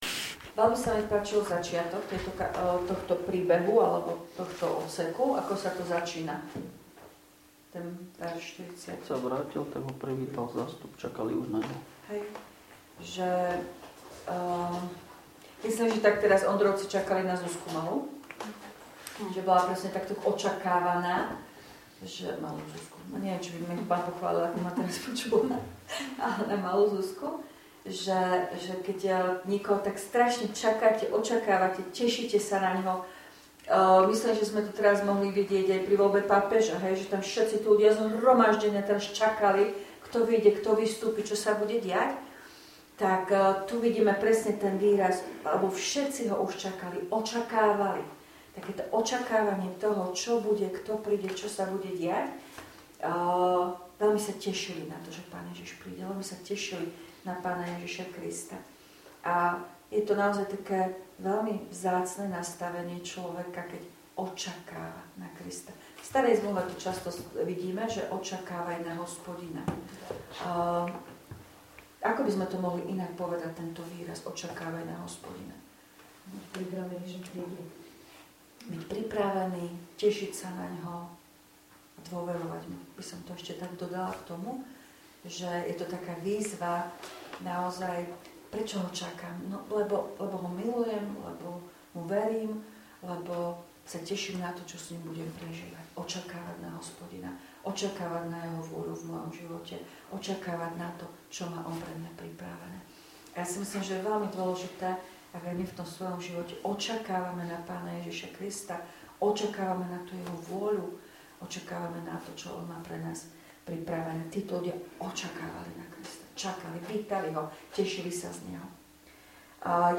Biblická hodina 21.5.2025
V nasledovnom článku si môžete vypočuť zvukový záznam z biblickej hodiny zo dňa 21.5.2025.